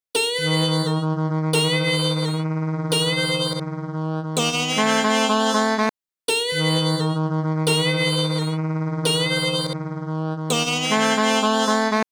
crazy blues that shows SFXloop in action.